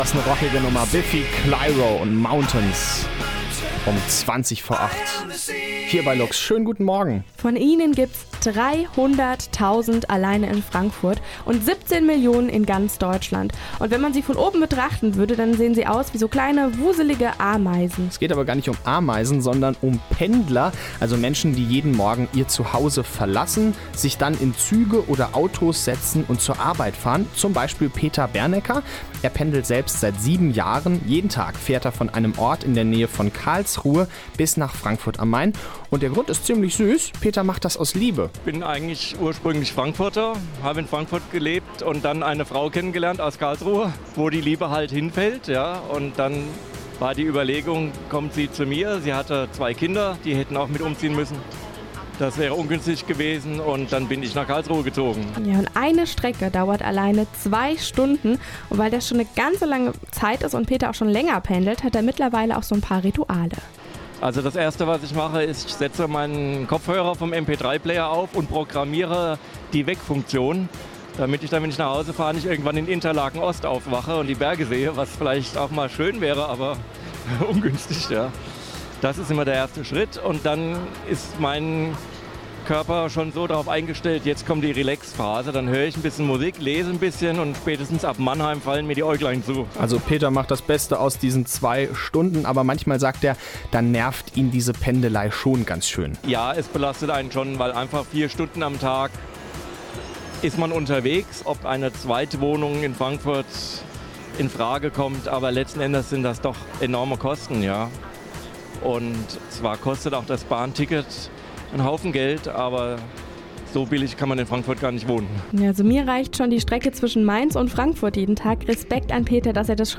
Wir haben uns mit einem Pendler getroffen und mit ihm gesprochen.